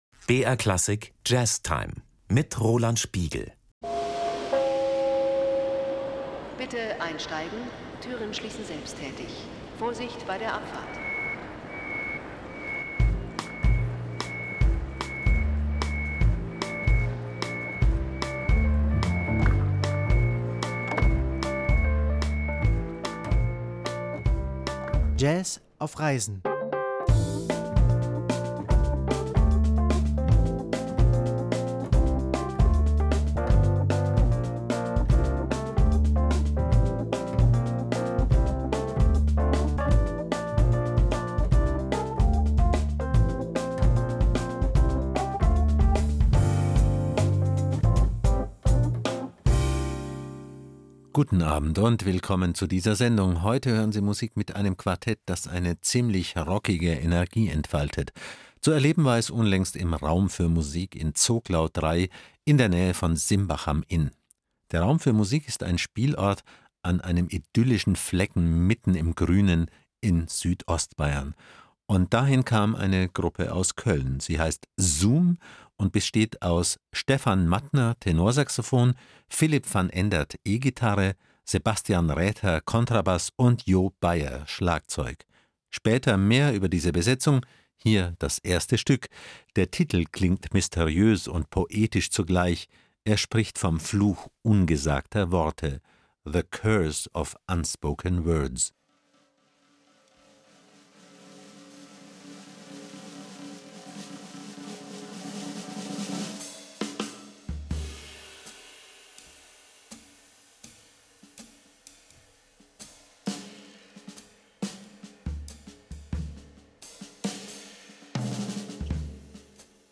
Jazz mit Drive, Blues und wacher Kommunikation.
Tenorsaxophon
Gitarre
Bass
Schlagzeug.